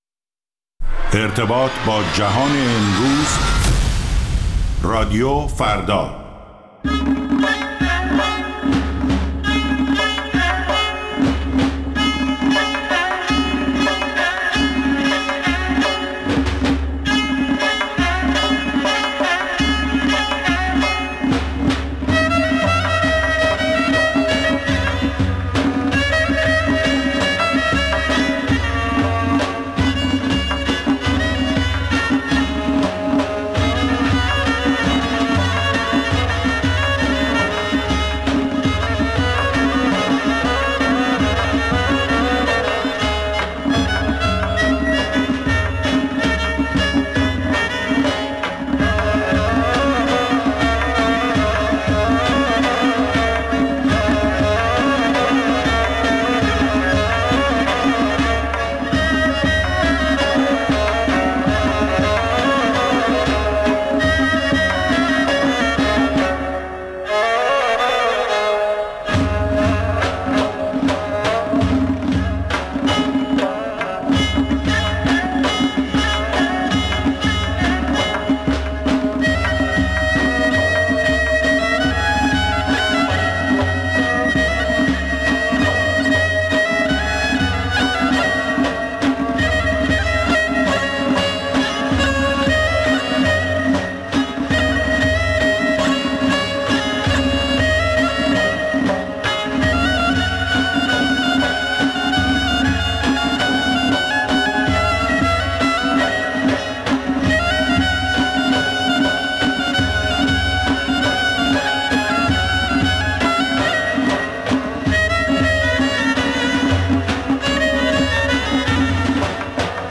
ویژه برنامه موسیقی محلی ایران